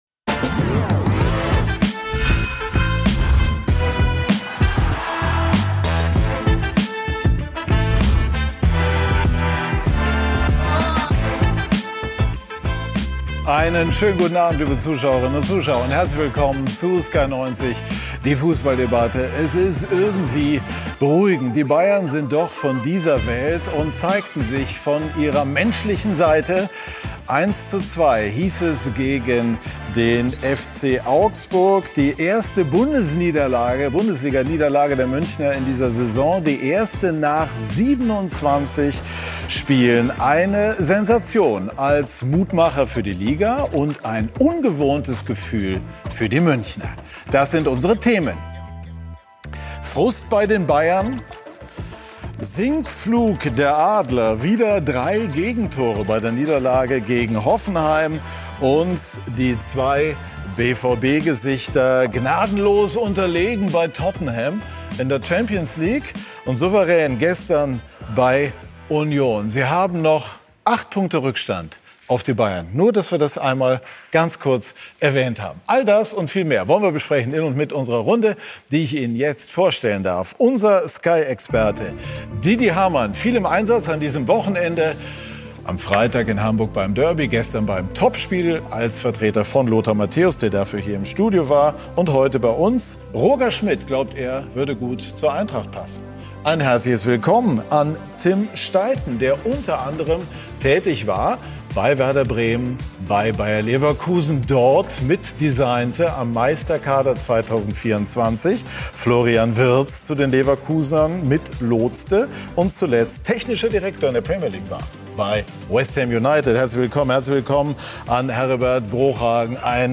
Beschreibung vor 2 Monaten Kontrovers, unterhaltsam, meinungsbildend – mit Sky90 präsentiert Sky den umfassendsten Fußball-Live-Talk Deutschlands. Immer sonntags ab 18:00 Uhr begrüßt Moderator Patrick Wasserziehr kompetente Gäste im Sky Studio.
Dietmar Hamann: Sky-Experte und Champions League Sieger